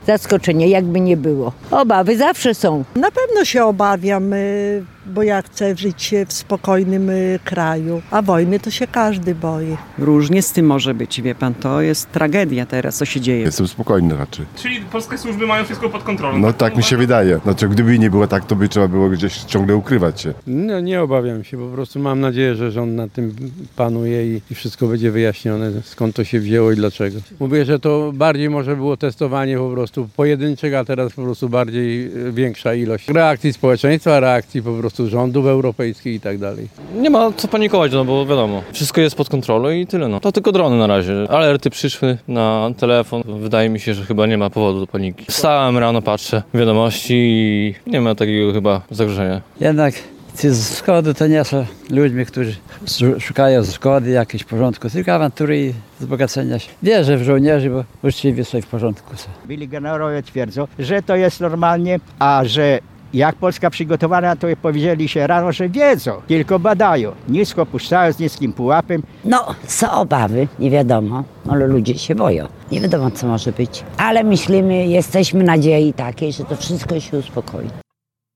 Sonda: Rosyjskie drony wleciały do Polski. Czy mieszkańcy Łomży obawiają się eskalacji?
W odpowiedziach osób, z którymi rozmawiał nasz reporter, dominuje spokój i zaufanie do polskich służb.